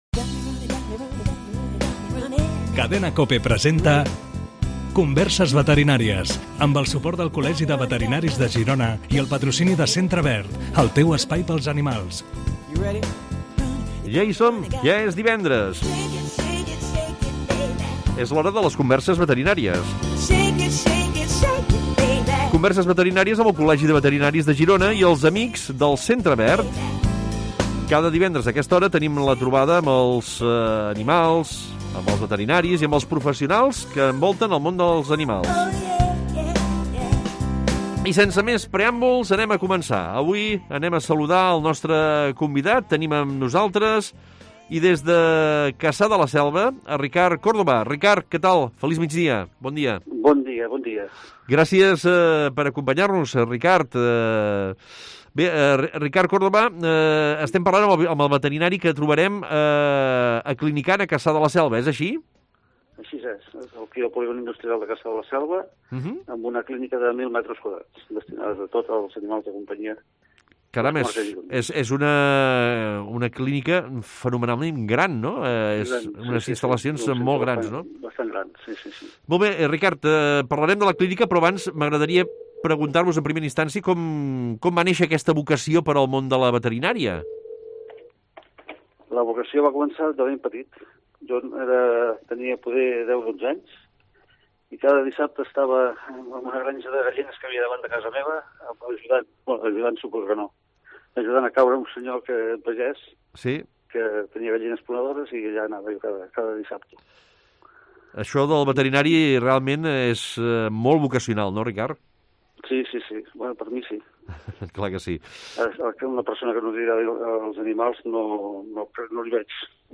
Es contesta per ràdio a les preguntes de propietaris de gossos i gats.